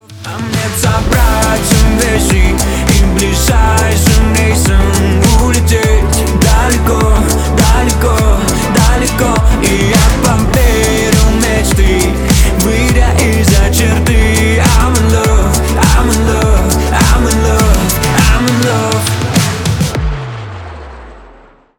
• Качество: 320, Stereo
теплые